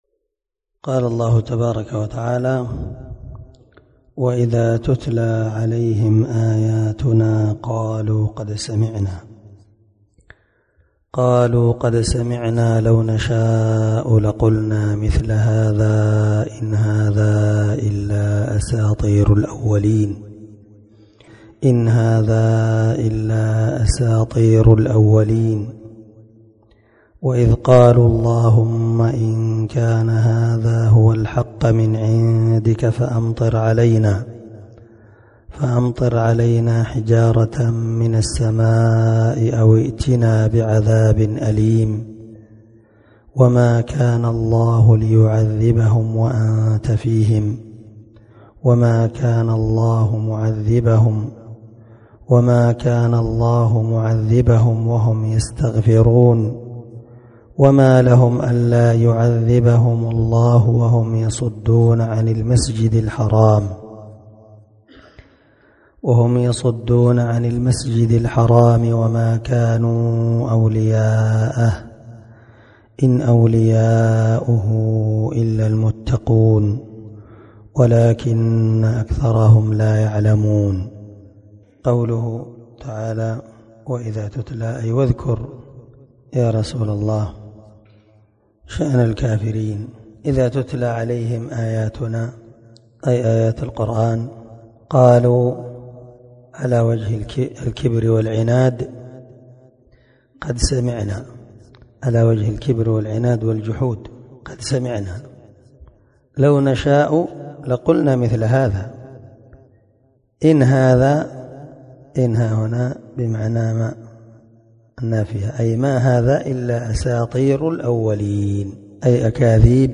514الدرس11 تفسير آية (31_ 34) من سورة الأنفال من تفسير القران الكريم مع قراءة لتفسير السعدي
دار الحديث- المَحاوِلة- الصبيحة.